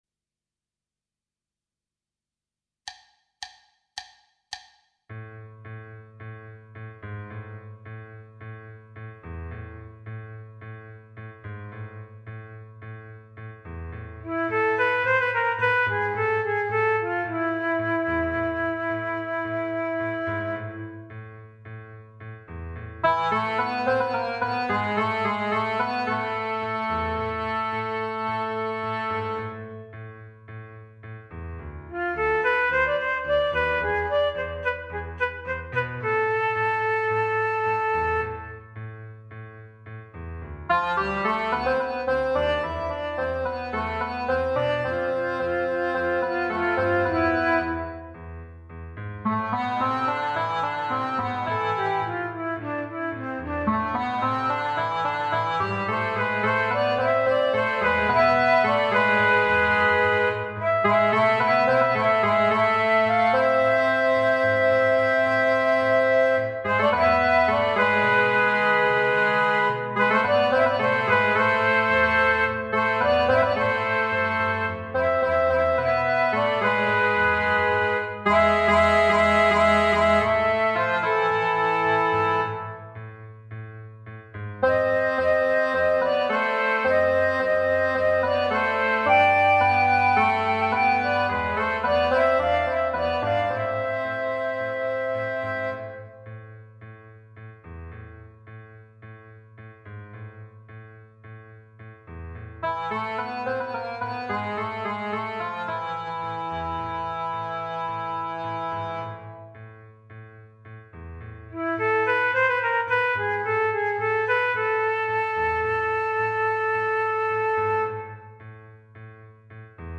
minus Piano